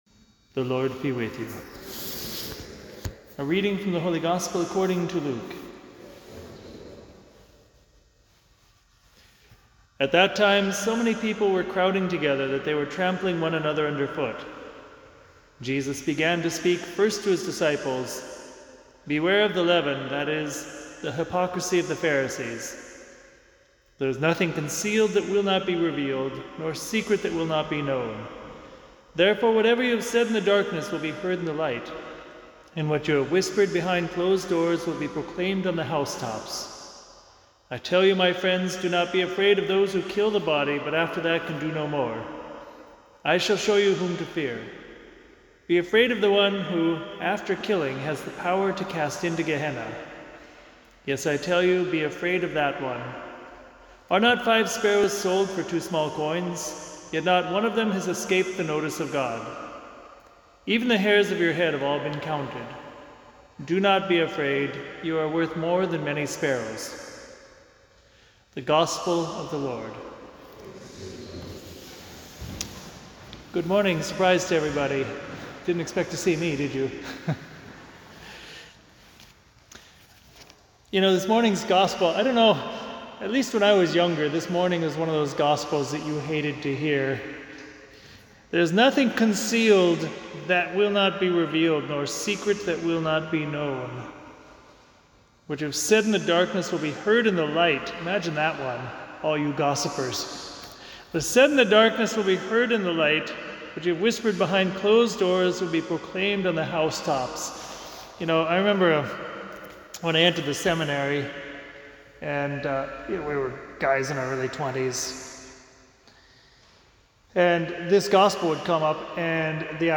Family Friday Homily - RC NY Tri-State